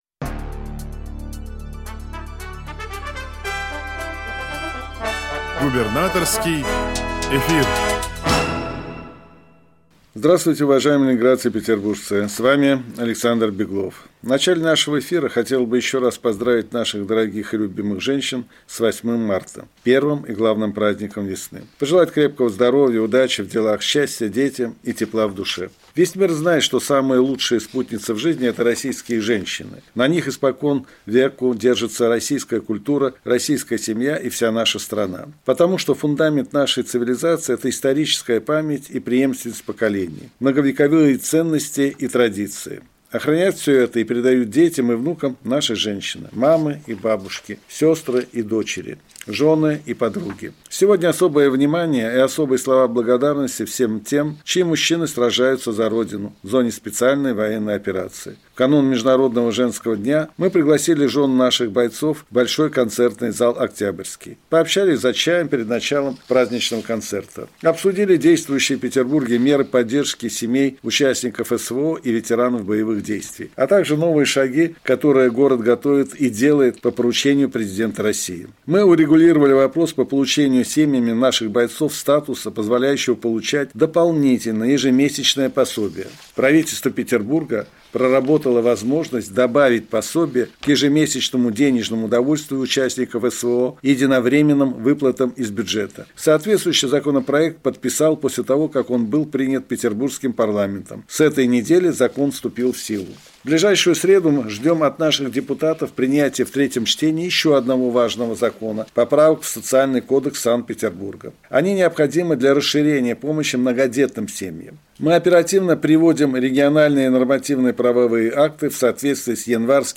Радиообращение – 11 марта 2024 года